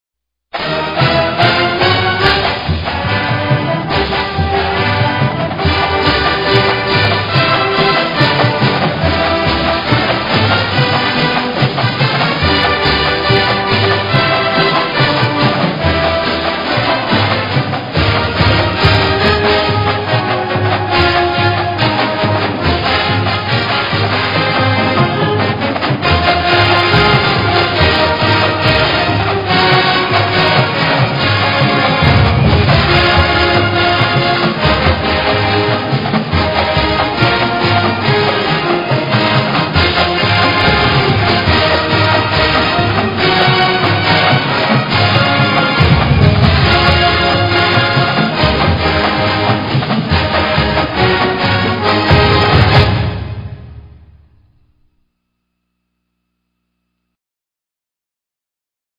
Tennessee Fight Song
(Played after the PAT)